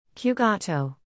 zo-spreek-je-qugato-uit.mp3